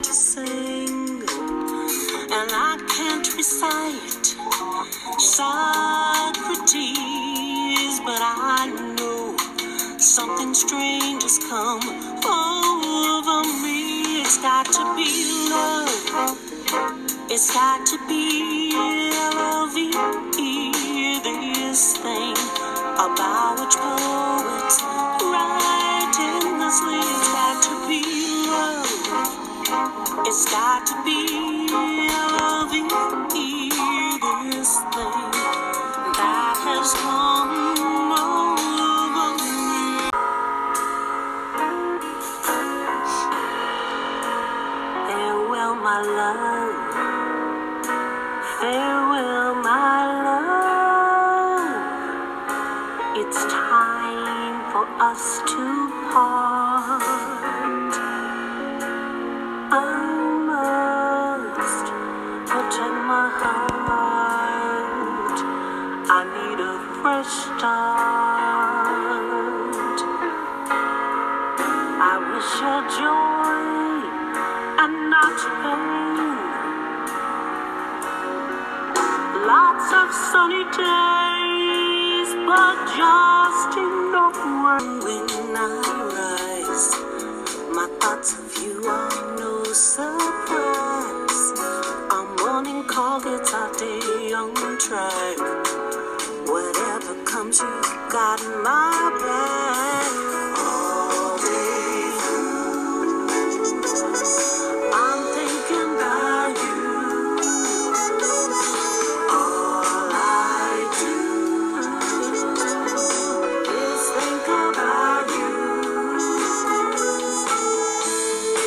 T-shirt for a vocalist's album release with music clip